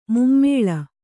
♪ mummēḷa